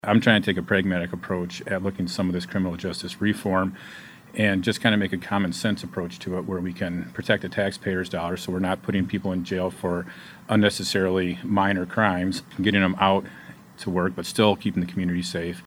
Rep. Mueller, a retired sheriff’s deputy who is currently serving on the Governor’s Michigan Joint Task Force on Jail and Pretrial Incarceration, talks about his work to make government work better through criminal justice reforms that protect taxpayers while also keeping the public safe.